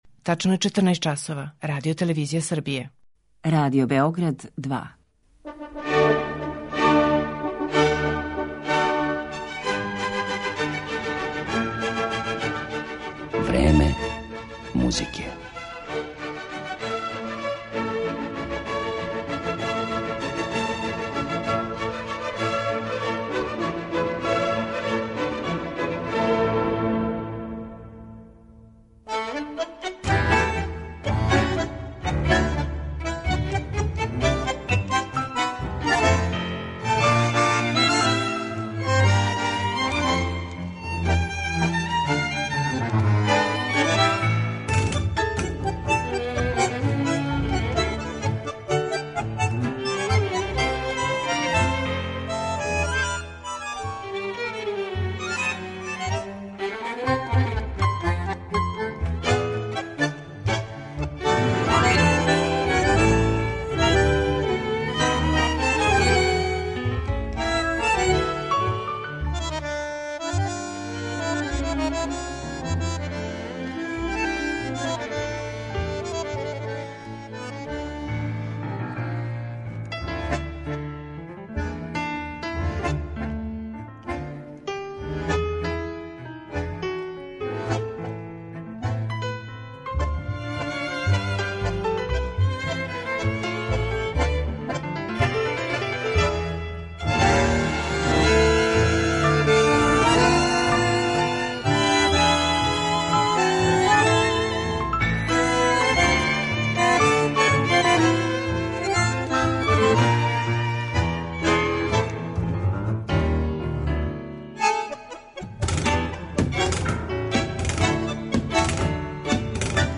Представићемо радове аргентинских мајстора танга